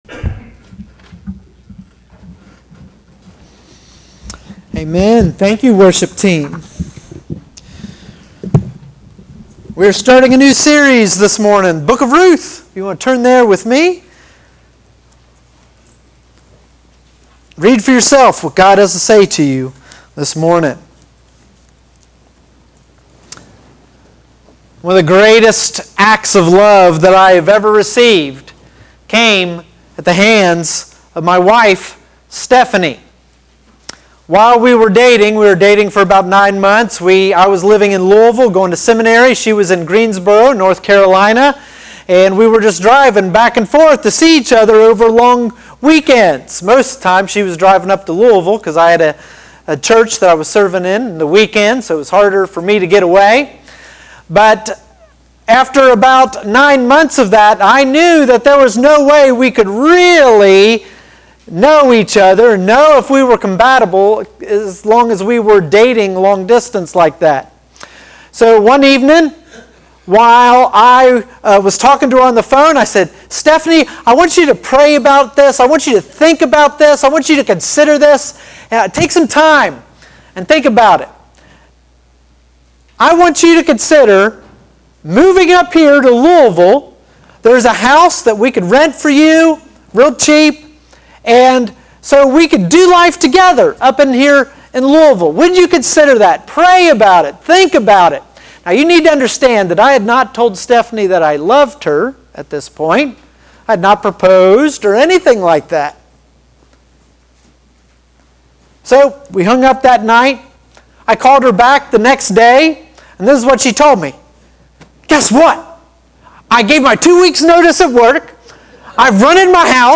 Sermons | Flint Hill Baptist Church